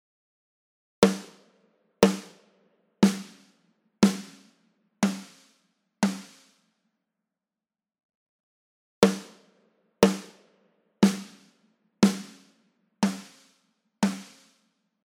スネアドラム
力強い音。真ん中とフチの金属の部分を同時に叩く。
基本。真中を叩く。
↓の音源は、上記3つを順番に叩いた例です。
snare.mp3